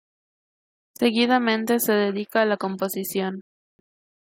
/komposiˈθjon/